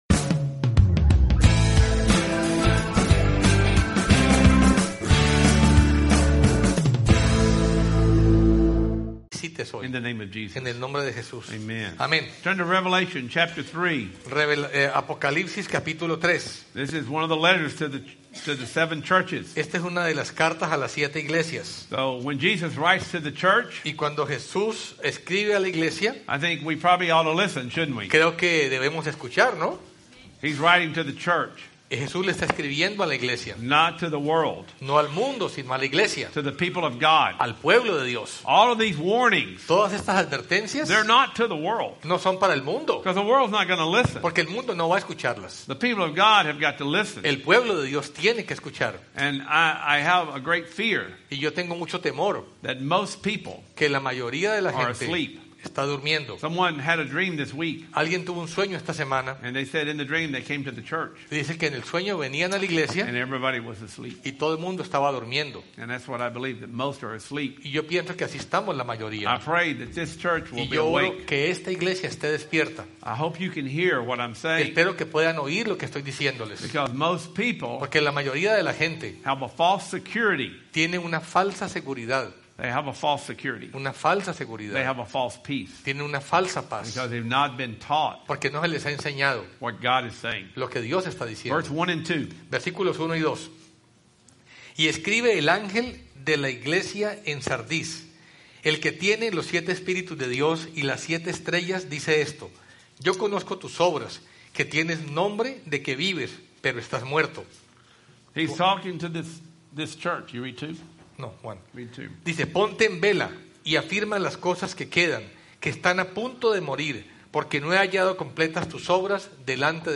Wisdom of God Service Type: Sunday Service « Pattern & Design for Apostolic Living Pt. 1 The Thief is Coming